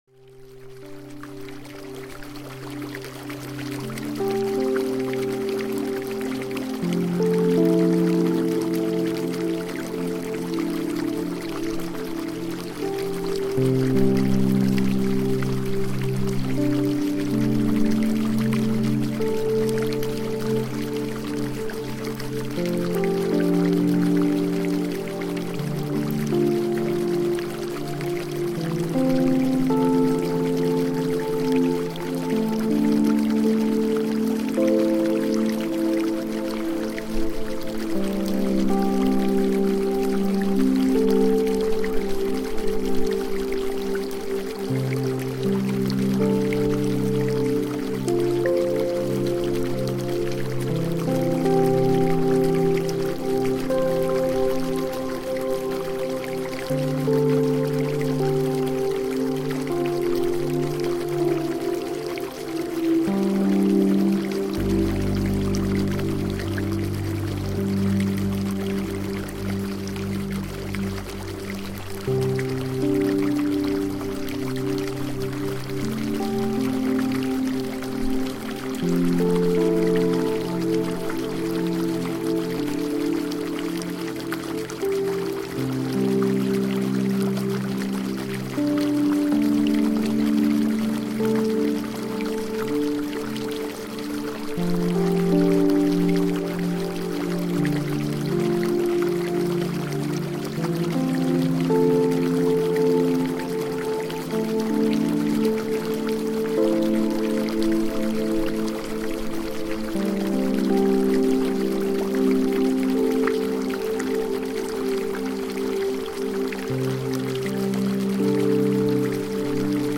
Ambient Stream and Forest Rainfall to Calm the Nervous System for Gentle Concentration for Gentle Concentration – Evening Wind Mix to Calm the Nervous System
Each episode of Send Me to Sleep features soothing soundscapes and calming melodies, expertly crafted to melt away the day's tension and invite a peaceful night's rest.